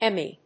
音節Em・my 発音記号・読み方
/émi(米国英語), ˈemi:(英国英語)/